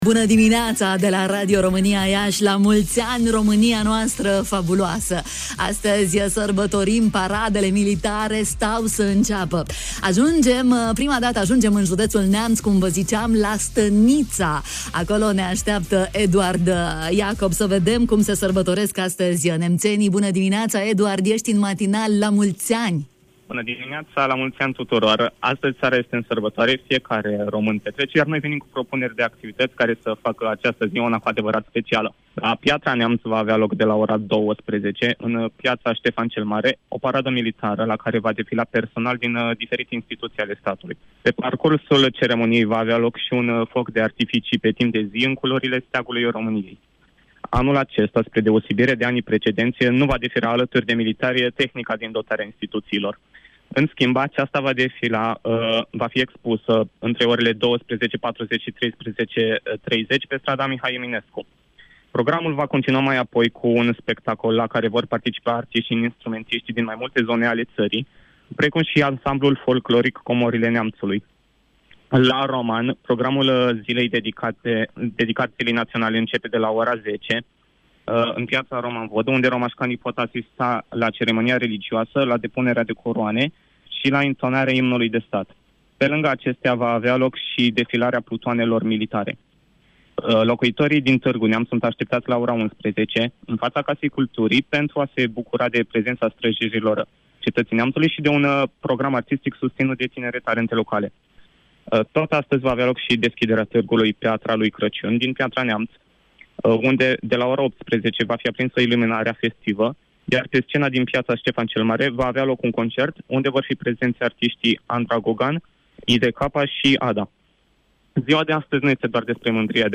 Ediția specială a emisiunii „Bună dimineața” i-a avut corespondenți și pe tinerii de la Incubator Radio Romania Iasi